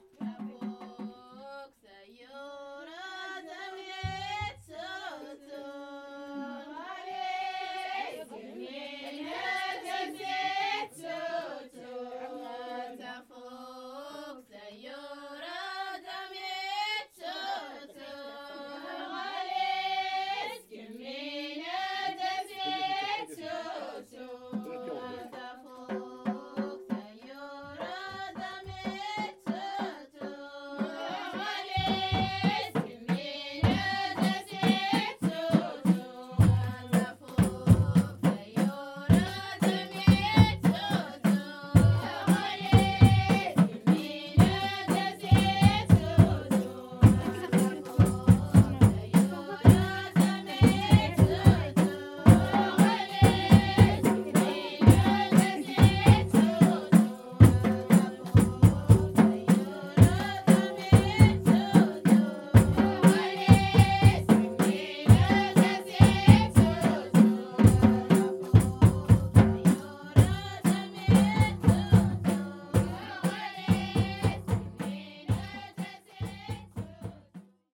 Prête moi tes yeux : Tizkmoudine chant traditionnel
Le soir, un groupe de musique vient parfois répéter au local.